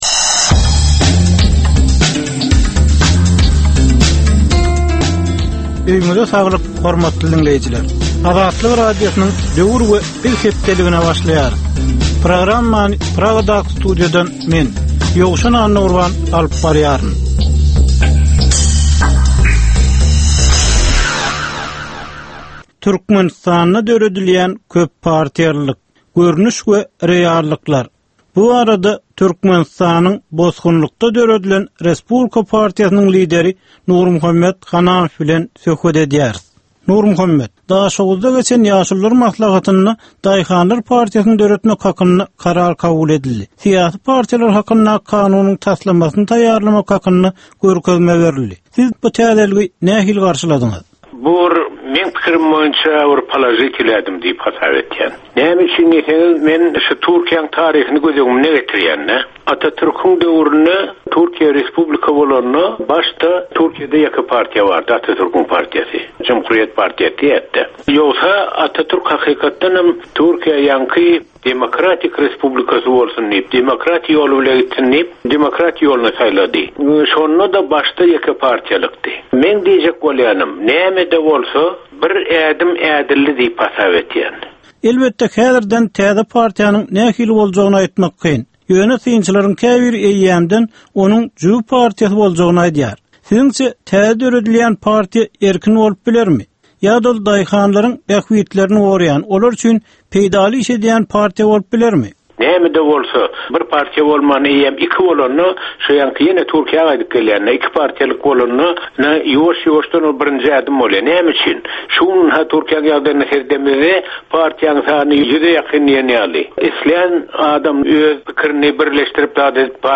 Türkmen jemgyýetindäki döwrüň meseleleri. Döwrüň anyk bir meselesi barada ýörite syn-gepleşik. Bu gepleşikde diňleýjiler, synçylar we bilermenler döwrüň anyk bir meselesi barada pikir öwürýärler, öz garaýyşlaryny we tekliplerini orta atýarlar.